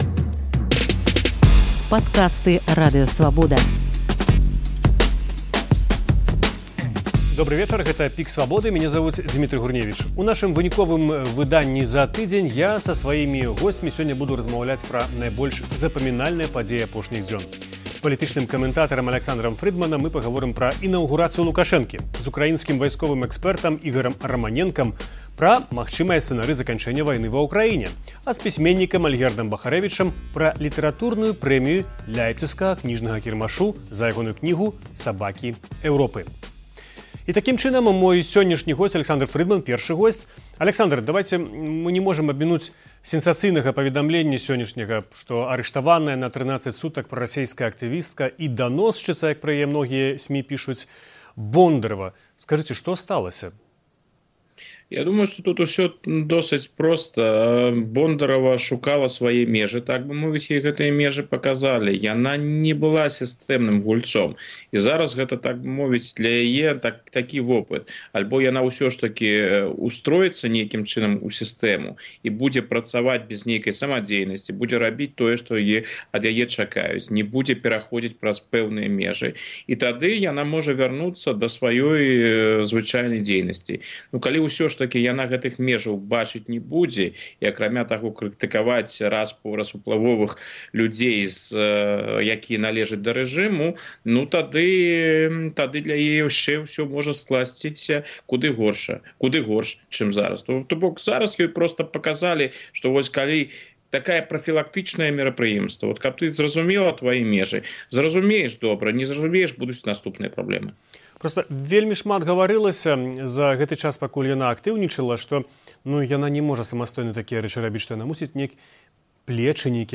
размаўляе са сваімі гасьцьмі пра найбольш запамінальныя падзеі апошніх дзён